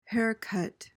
PRONUNCIATION: (HAIR-kuht) MEANING: noun: A reduction in value.